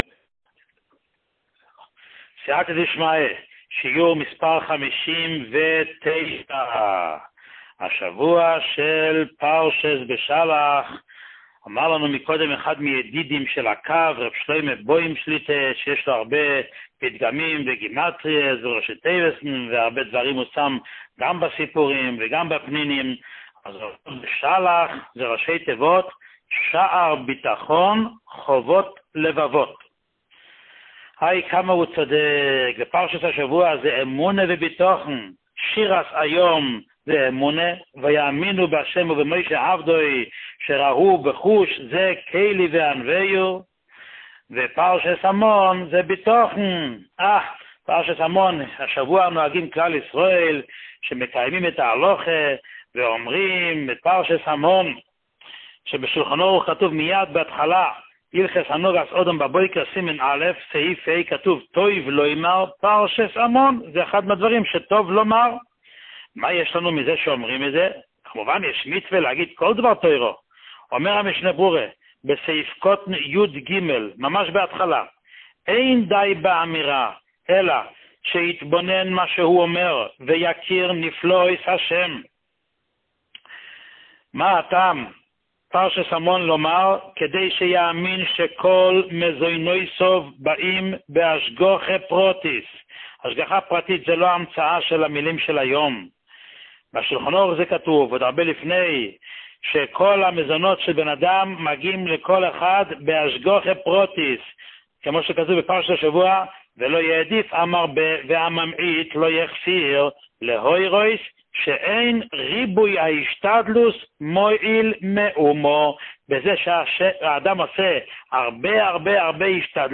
שיעורים מיוחדים
שיעור 59